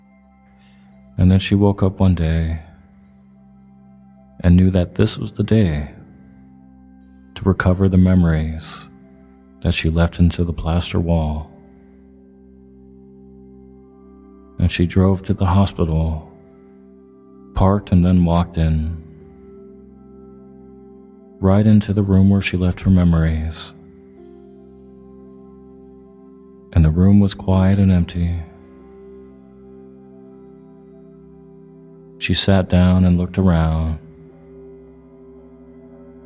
In this day-time meditation/hypnosis audio you’ll be using mental imagery to help release the negative charge of painful memories.